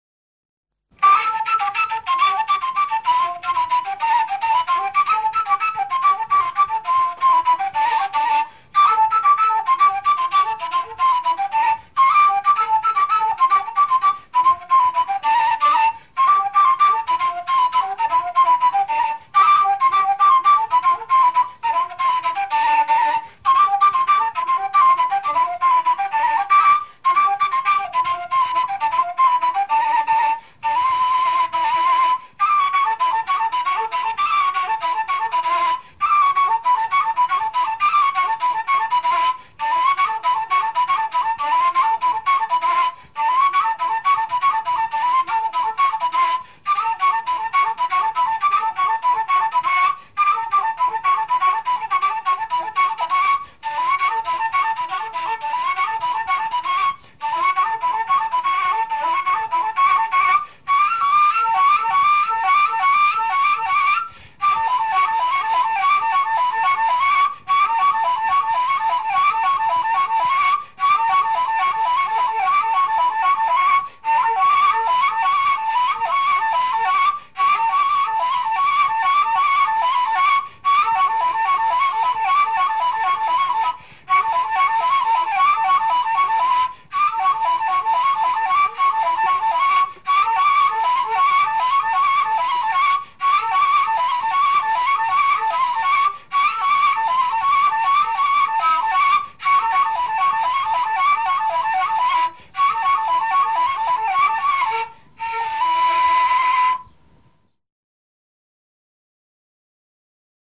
嘹亮、朴实、浑厚
长笛独奏